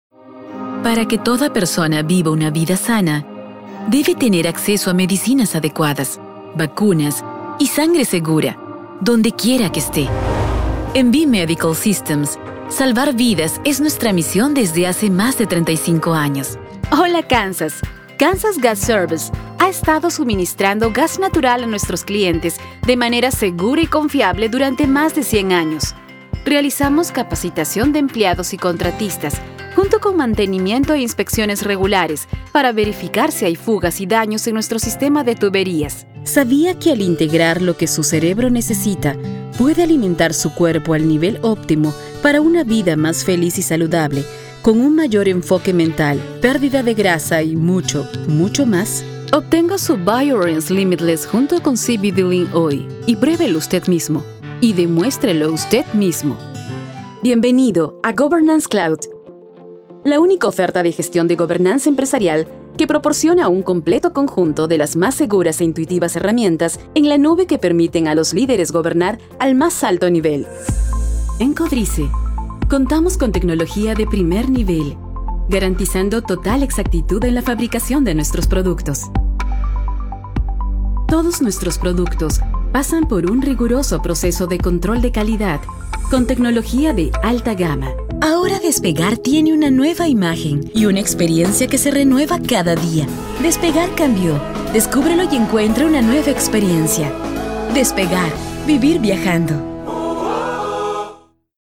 a Latin American Spanish female voice over artist from Peru. If you need a warm and elegant, but still natural and conversational voice,
Sprechprobe: Industrie (Muttersprache):